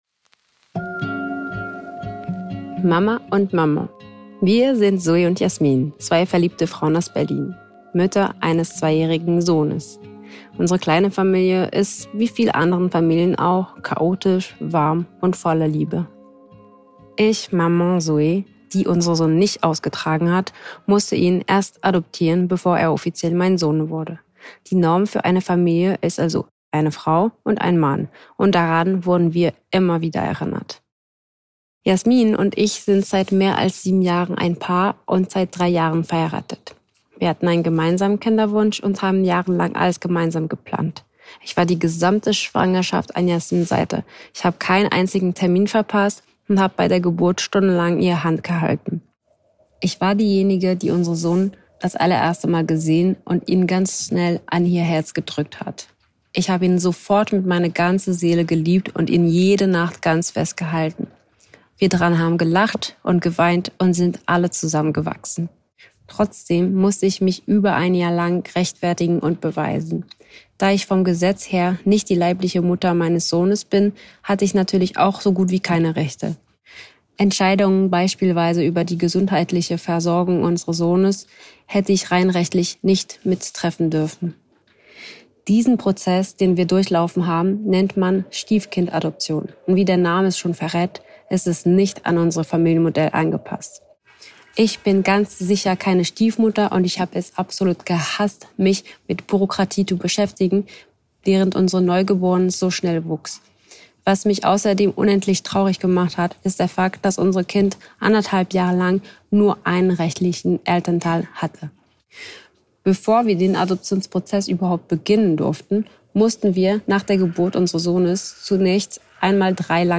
Audioartikel